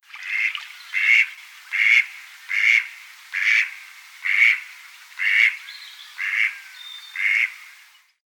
Звуки кряквы
На этой странице собраны натуральные звуки кряквы — от одиночных кряков до серийных повторений.
Голос кряквы у пруда